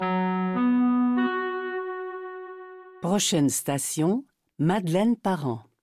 Listen to the métro voice pronounce the name Madeleine-Parent